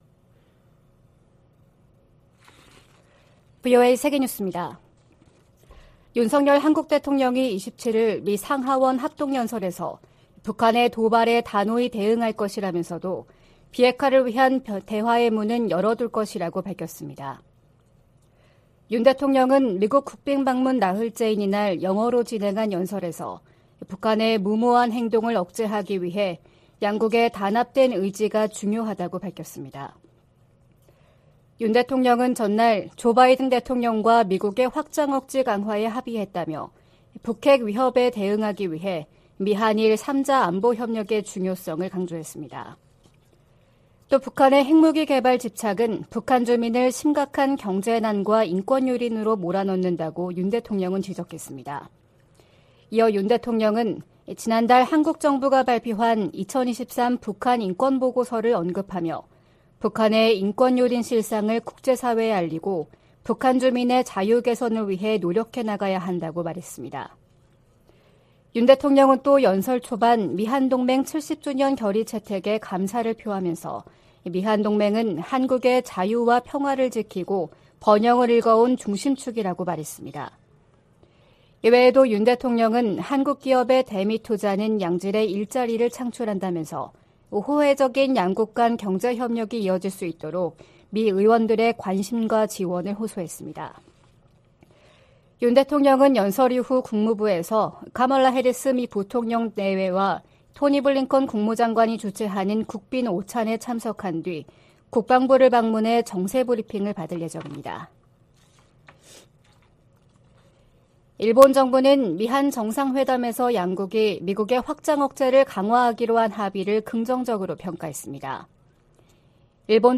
VOA 한국어 '출발 뉴스 쇼', 2023년 4월 28일 방송입니다. 조 바이든 미국 대통령과 윤석열 한국 대통령이 26일 백악관 회담에서 '워싱턴 선언'을 채택하고, 미한 핵협의그룹을 창설하기로 했습니다. 전문가들은 이번 정상회담에서 양국 관계가 '글로벌 포괄적 전략동맹'으로 격상을 확인했다고 평가했습니다.